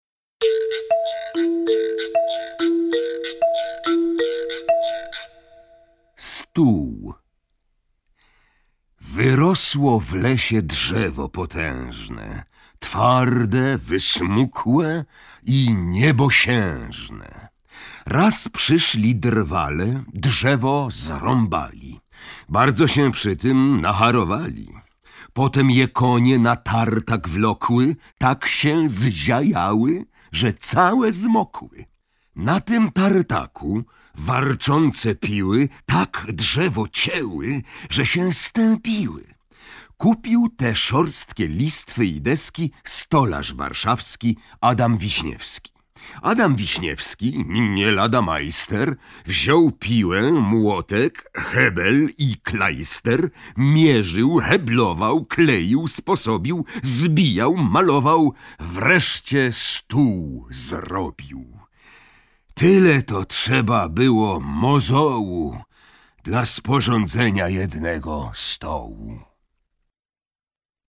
Piotr Fronczewski czyta wiersze dla dzieci Juliana Tuwima! Genialny głos i budująca atmosferę i napięcie narracja Piotra Fronczewskiego, a także inscenizacja audialna i muzyczna wykonana przez Teatr Polskiego Radia w Warszawie składają się na unikalny charakter tego wartościowego nagrania. Lokomotywa, Rzepka, Słoń Trąbalski, Okulary, Pan Maluśkiewicz, Ptasie radio i wiele innych wierszy Tuwima z tego audiobooku zauroczą małych i dużych wielbicieli wielkiej literatury!